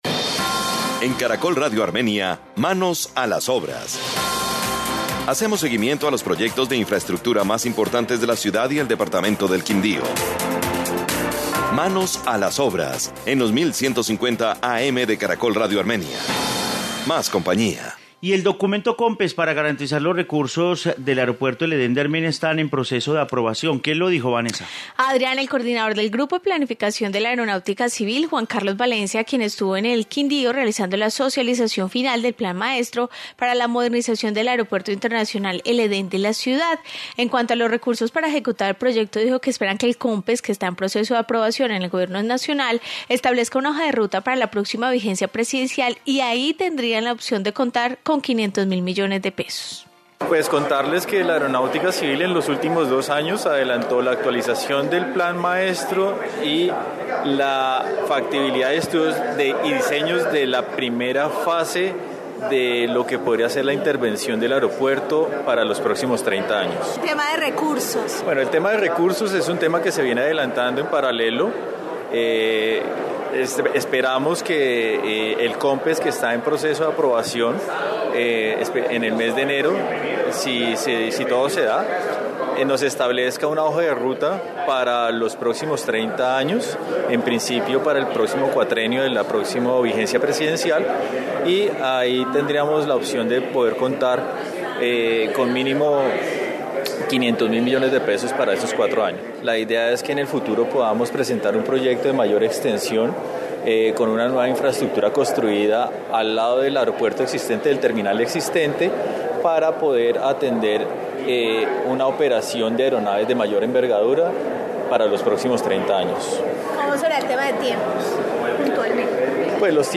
Informe sobre Aeropuerto El Edén de Armenia